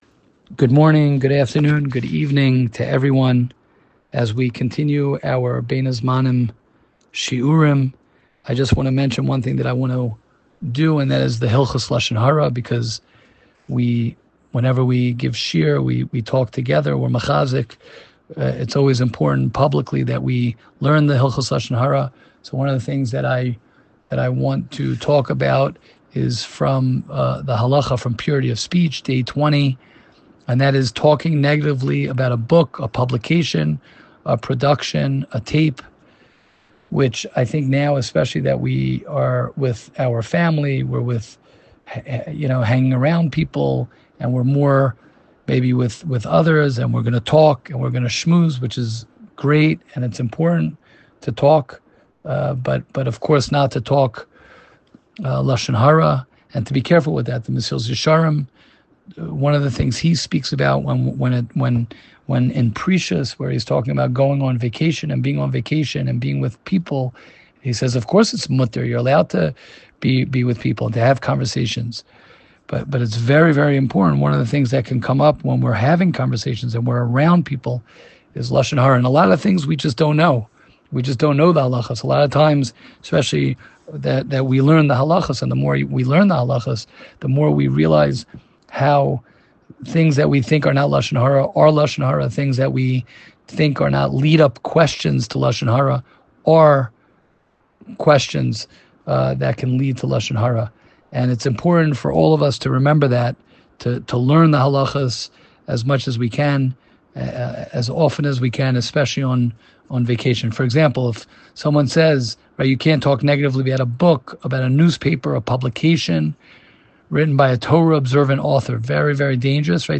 Bein Hazmanim Shiur